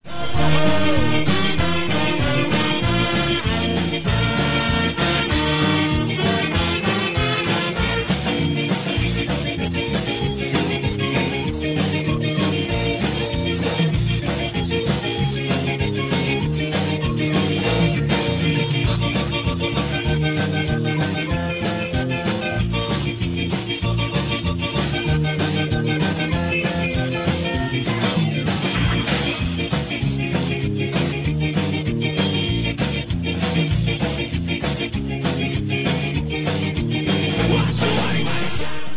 Ska italiano